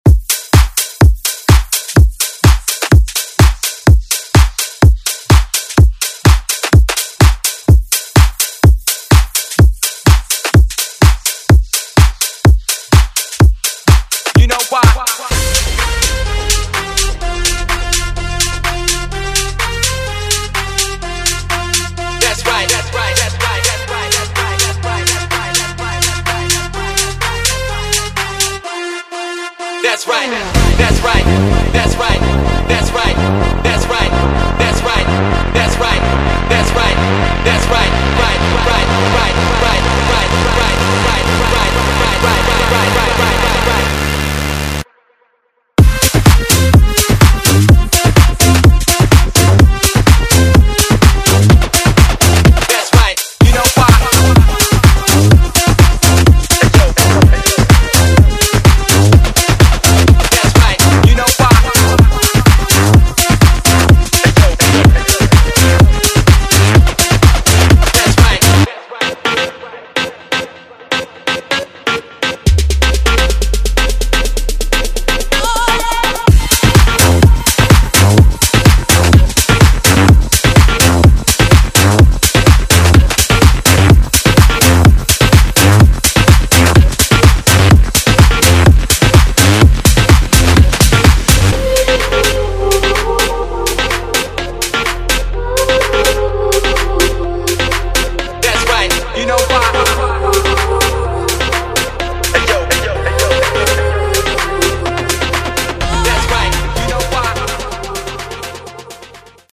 Genres: AFROBEAT , DANCE , RE-DRUM
Clean BPM: 128 Time